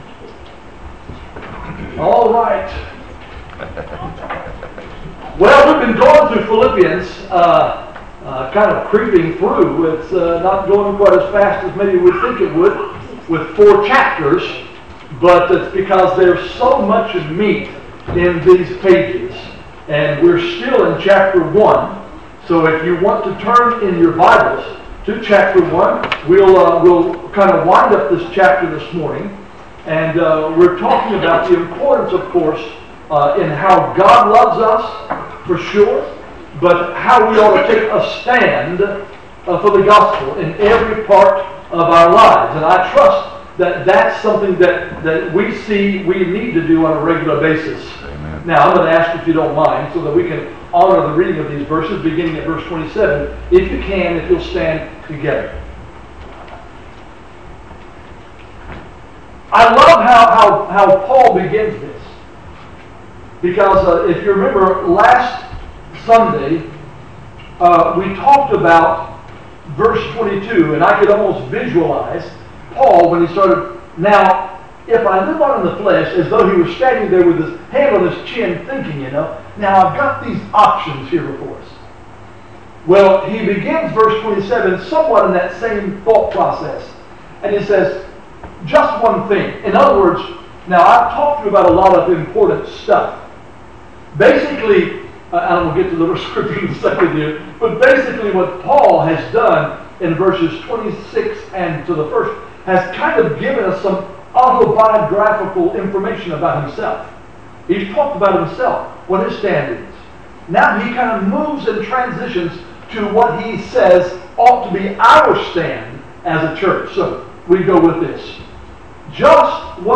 Sermons - Jackson Ridge Baptist Church